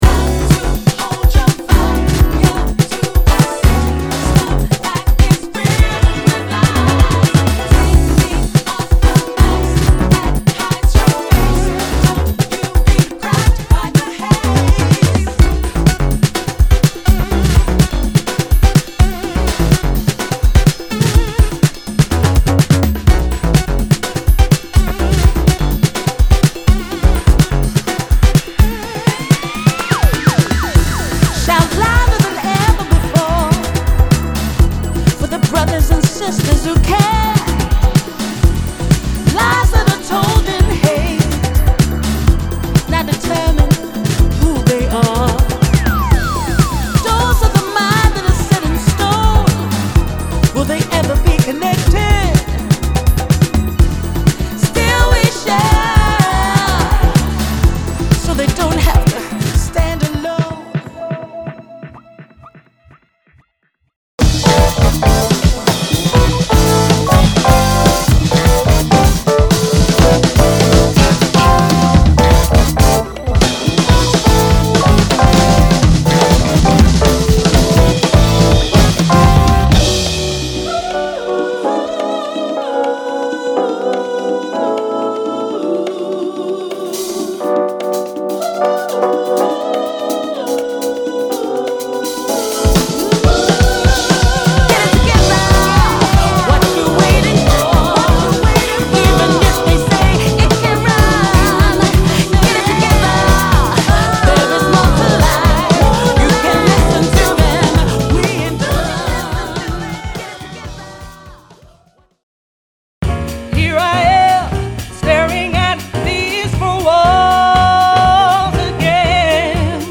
ジャズ、ハウス、ディスコ、R&B、ブギー、ブロークン・ビーツを網羅した内容が、東京の夜を彩る!!